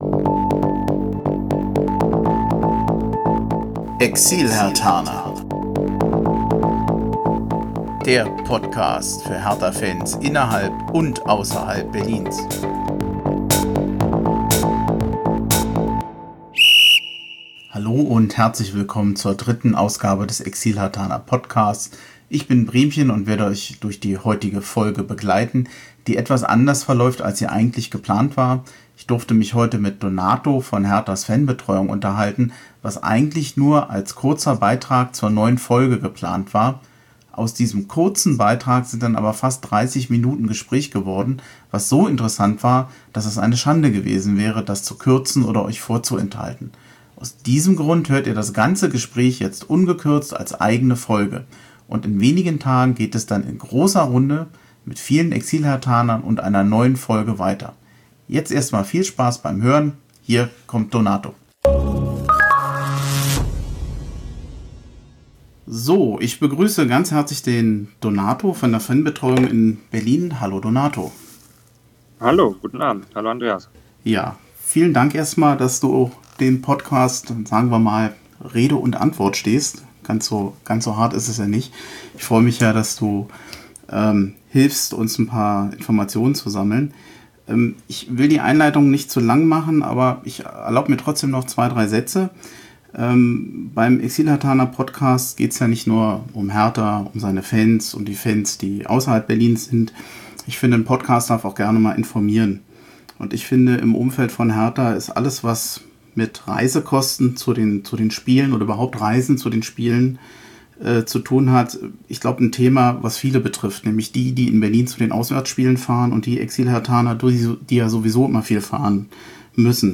Im Gespräch mit der Fanbetreuung (3)